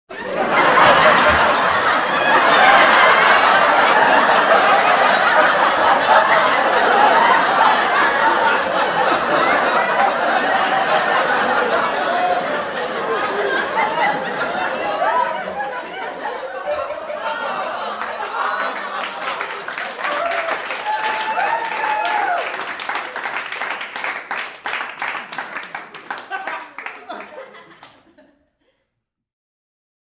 Sitcom Laughter Applause - Gaming Sound Effect (HD)